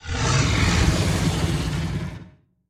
Sfx_creature_bruteshark_idle_01.ogg